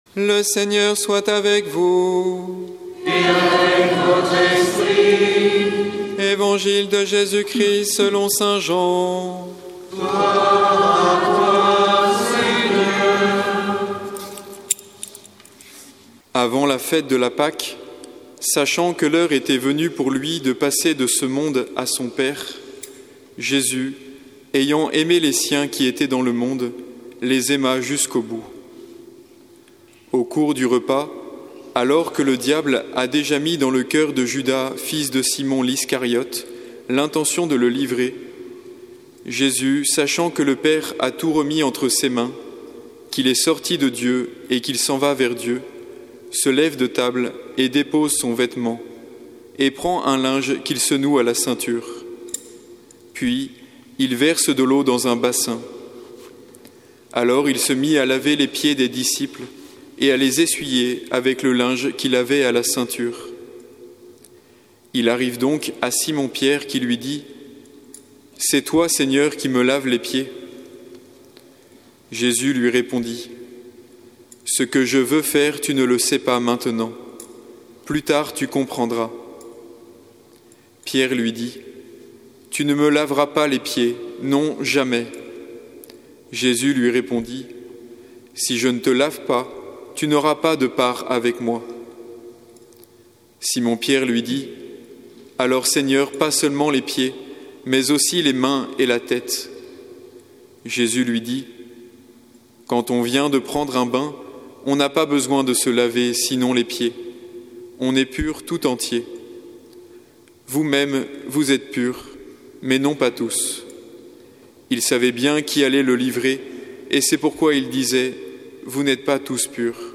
Évangile de Jésus Christ selon saint Jean avec l'homélie